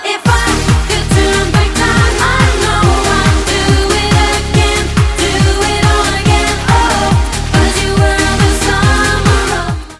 belgijska wokalistka